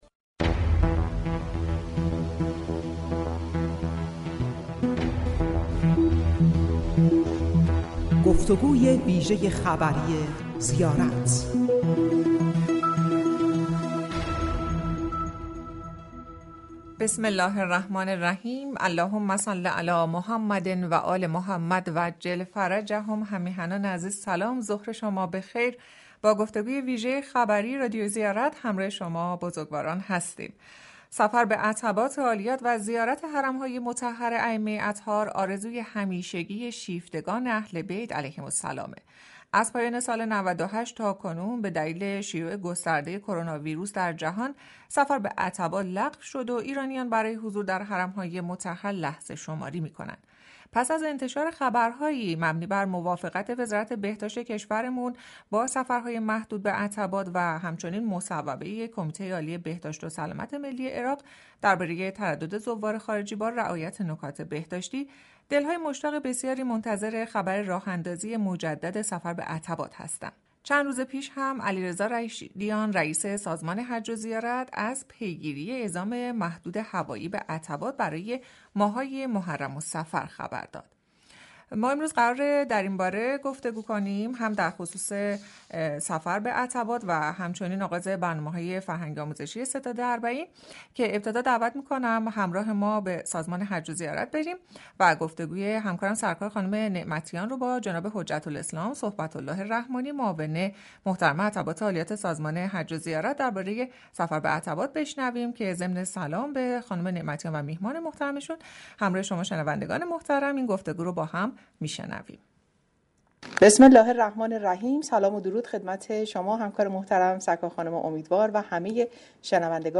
به گزارش خبر ررادیو زیارت ، حجت الاسلام صحبت الله رحمانی معاون عتبات عالیات سازمان حج و زیارت در گفتگوی ویژه خبری رادیو زیارت گفت : تا قبل از محرم تکلیف سفرهای زیارتی مشخص می شود .